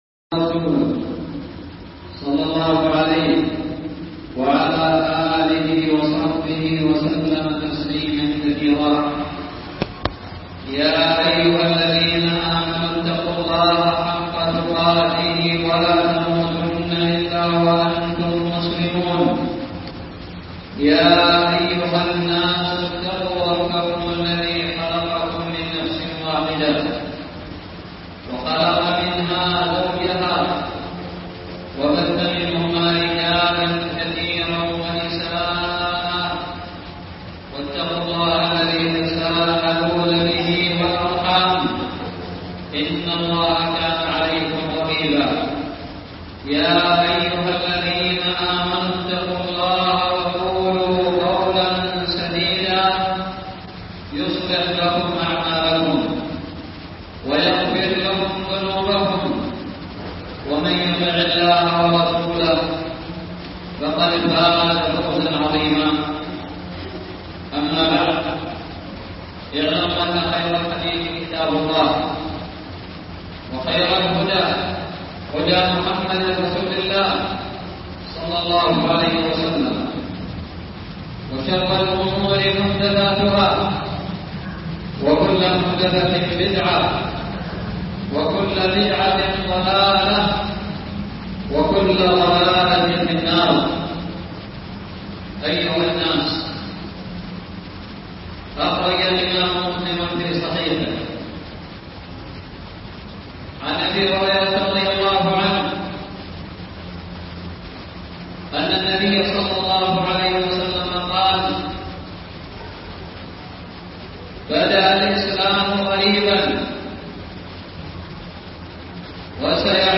خطب الجمعة
ألقيت بدار الحديث السلفية للعلوم الشرعية بالضالع في 25 محرم 1439هــ